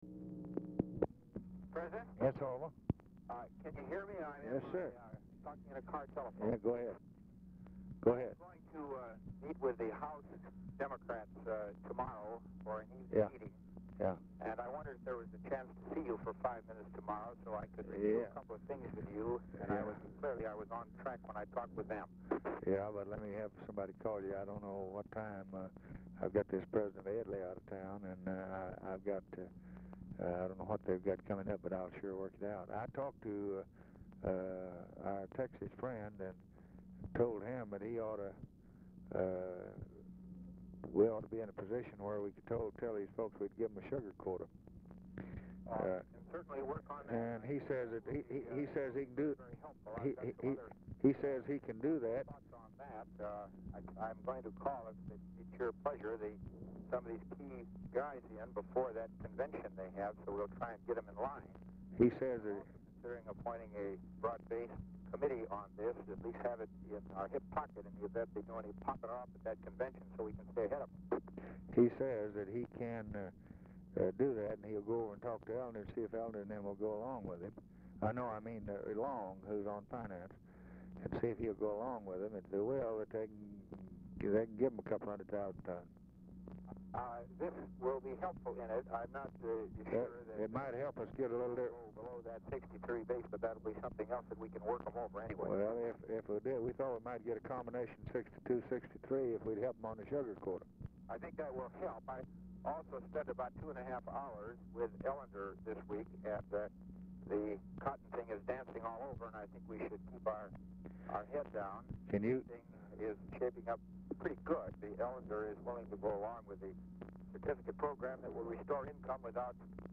FREEMAN IS CALLING FROM CAR PHONE
Format Dictation belt
Location Of Speaker 1 Oval Office or unknown location
Specific Item Type Telephone conversation